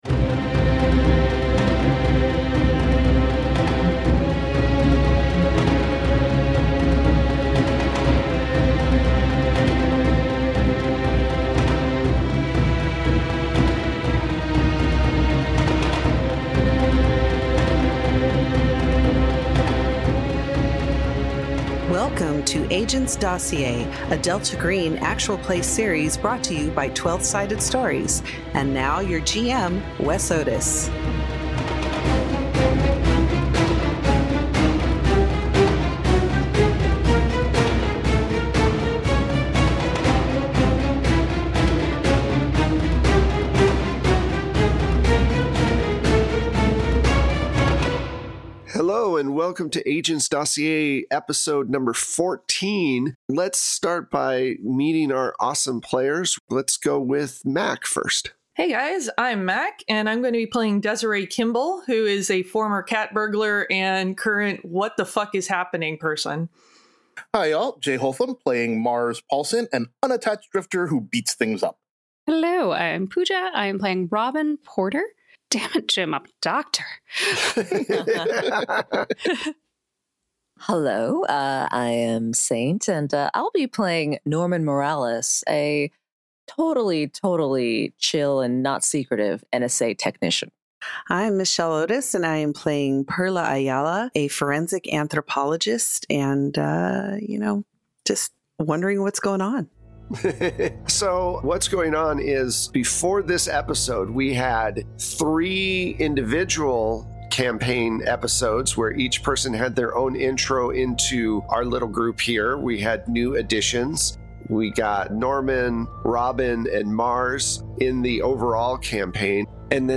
Worlds beyond imagination await you! At Twelve-Sided Stories, we bring tales to life through TTRPGs, with fully produced sound effects and music.